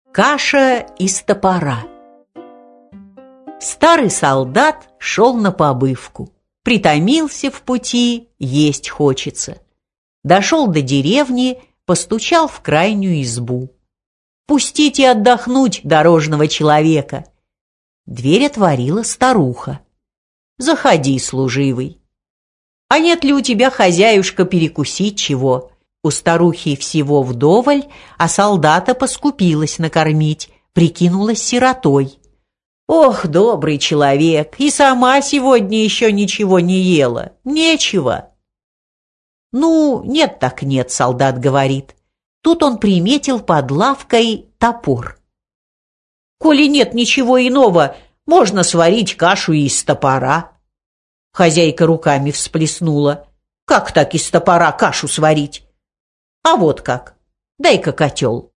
Аудиокнига Каша из топора | Библиотека аудиокниг